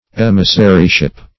Emissaryship \Em"is*sa*ry*ship`\, n. The office of an emissary.